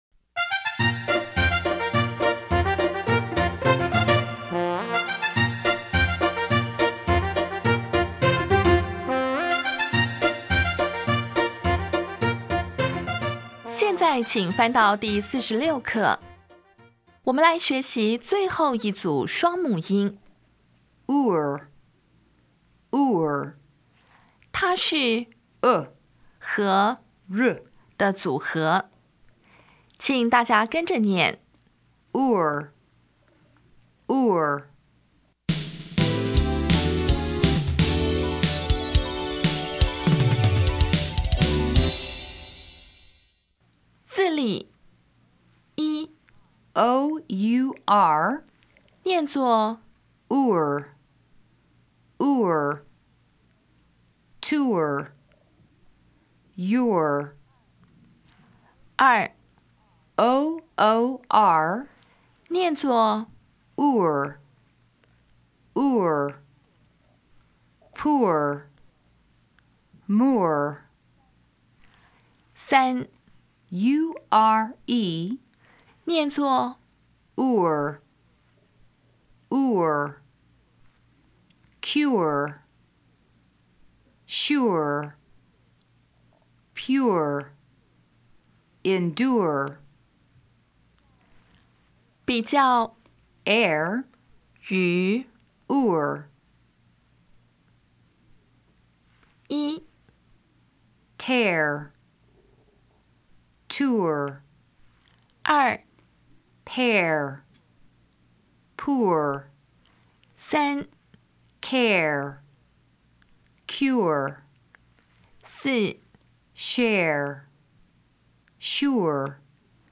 音标讲解第四十六课
[tʊr]
[pʊr]
比较 [ɛr] [ʊr]
Listening Test 18